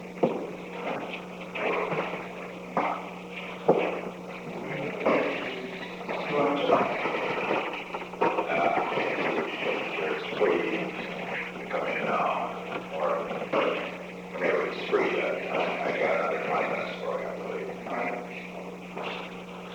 Secret White House Tapes
Conversation No. 637-14
Location: Oval Office
The President met with Alexander P. Butterfield.